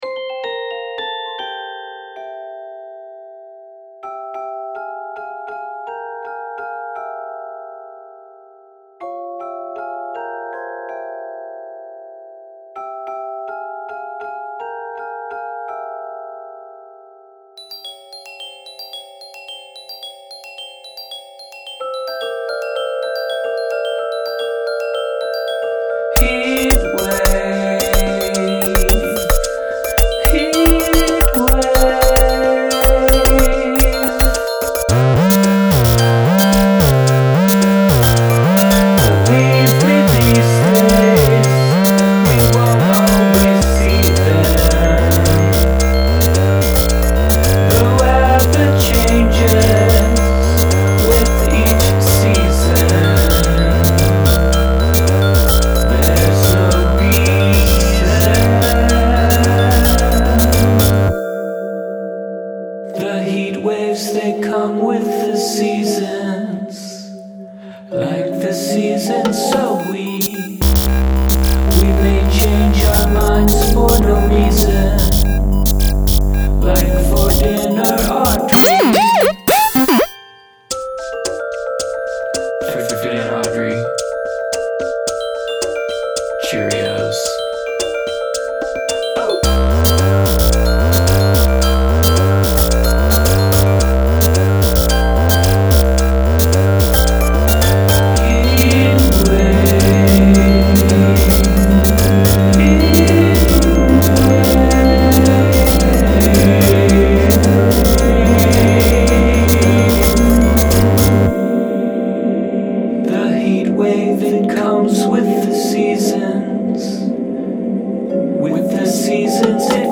It's all basically C major
verse, chorus, verse, chorus